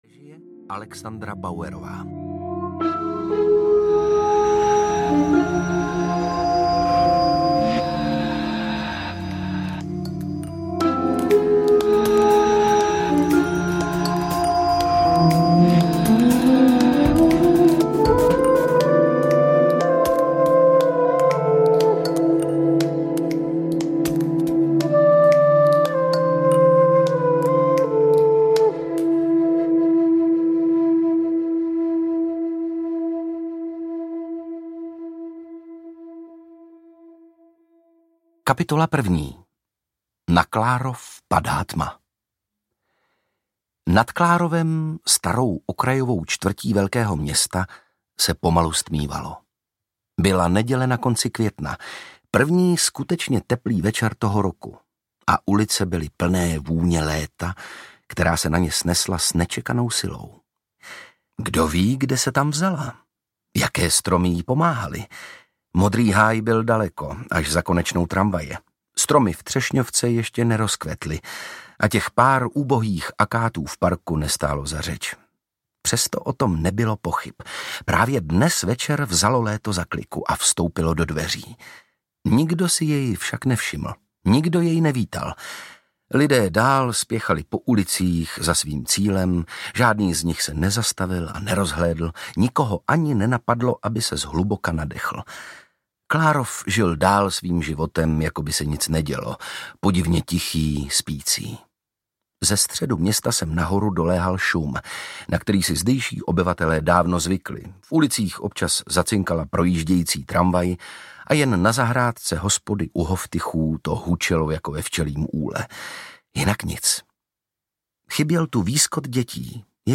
Tajemství jeskyně pokladů audiokniha
Ukázka z knihy
• InterpretOndřej Brousek
tajemstvi-jeskyne-pokladu-audiokniha